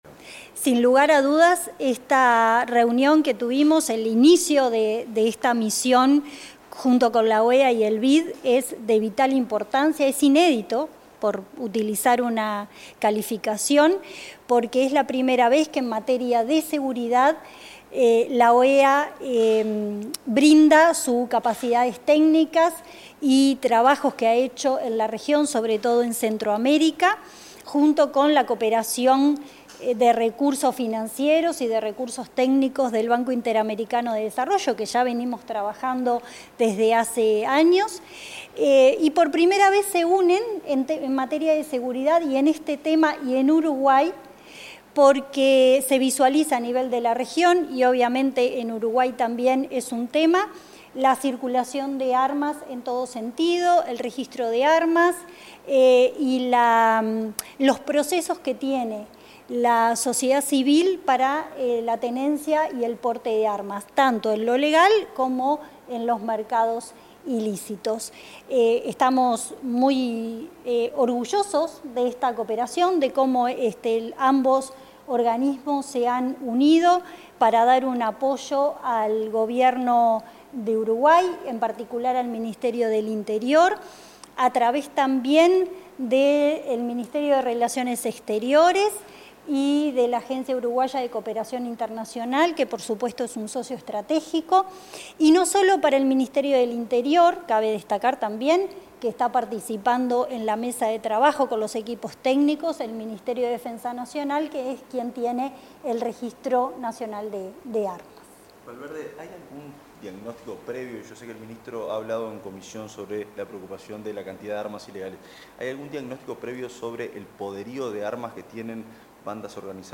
Declaraciones de la subsecretaria de Interior, Gabriela Valverde